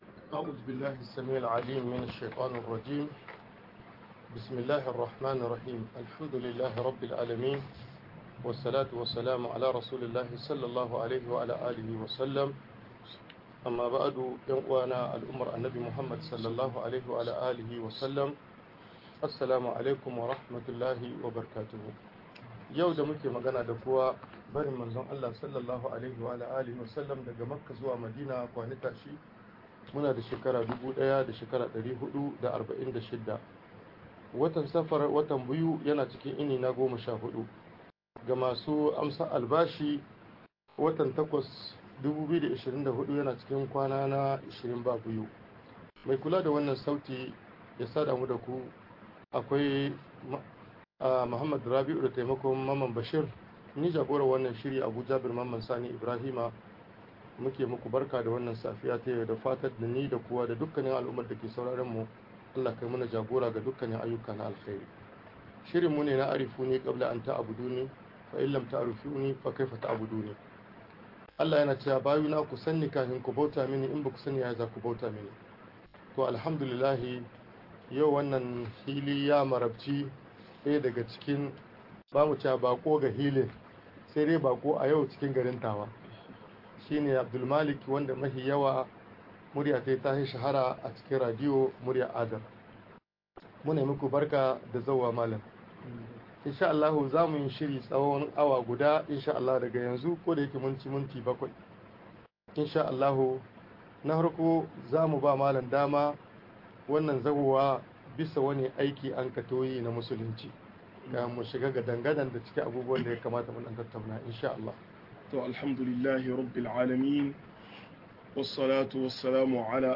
MUHADARA